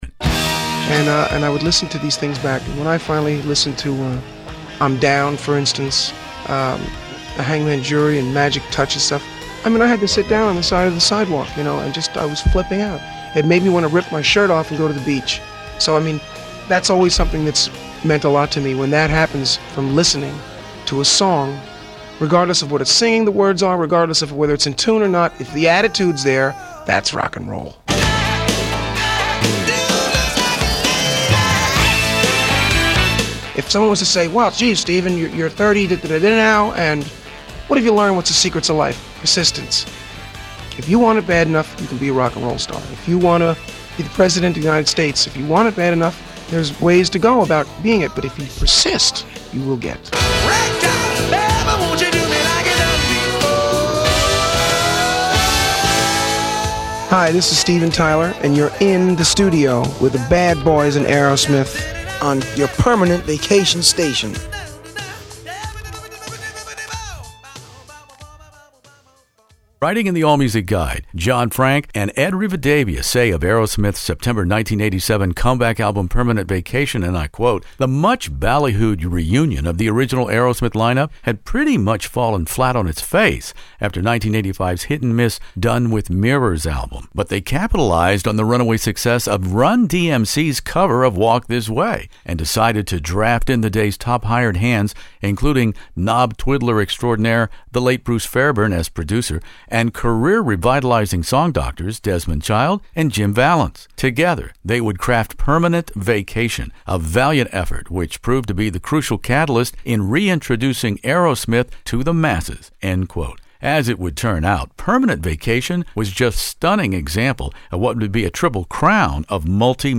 Aerosmith "Permanent Vacation" interview In the Studio